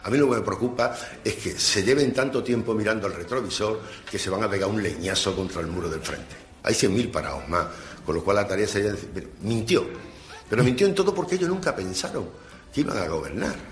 Pues ayer habló con Europa press y, para empezar el año, culpó al gobierno andaluz del deterioro de los servicios públicos. Dijo que Moreno se había comprometido a crear cien mil puestos de trabajo y que mintió.
Declaraciones de Juan María Cornejo